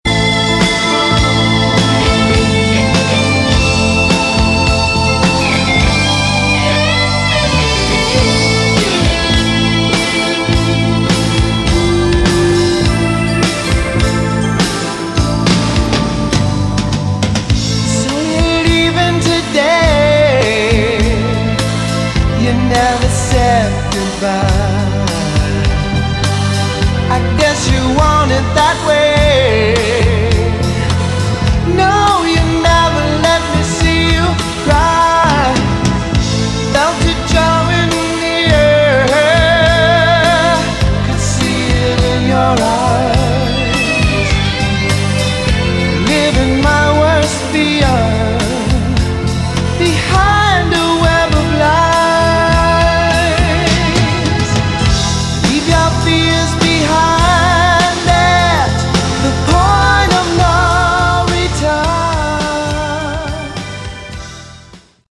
Category: Hard Rock
lead vocals, guitars
keyboards, backing vocals
drums, backing vocals
bass, backing vocals